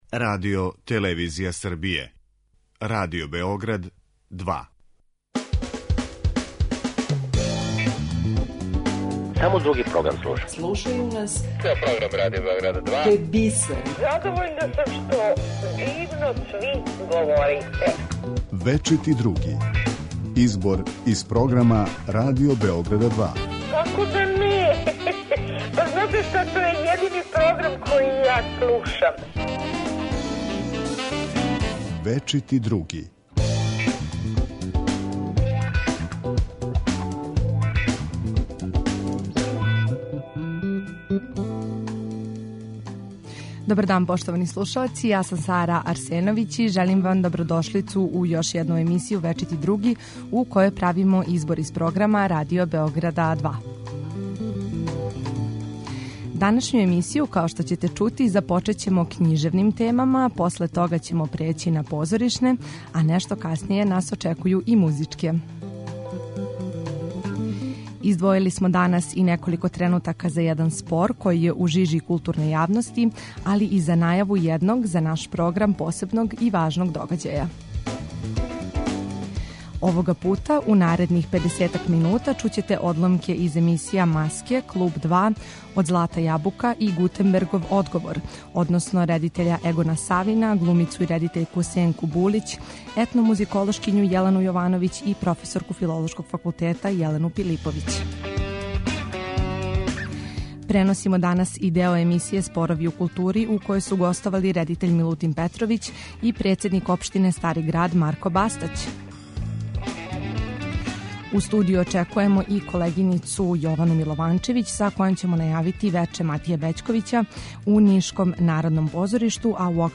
Избор из програма Радио Београда 2